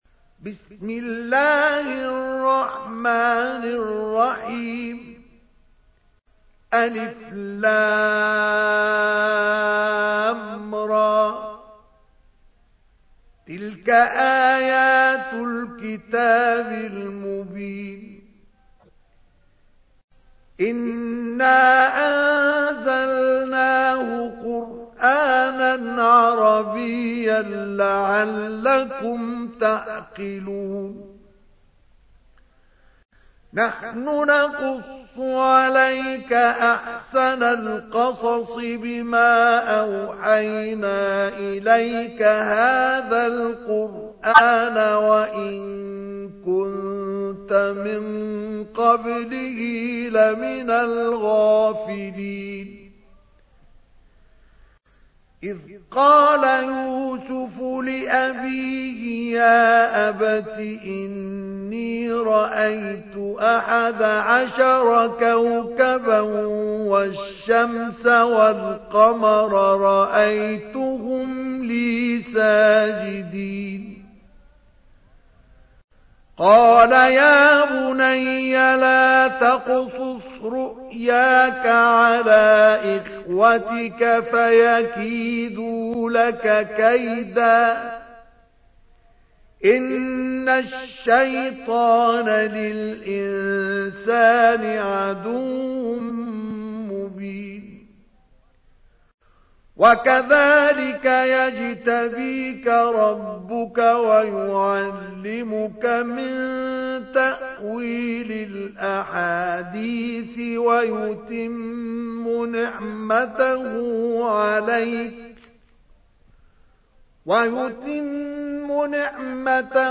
تحميل : 12. سورة يوسف / القارئ مصطفى اسماعيل / القرآن الكريم / موقع يا حسين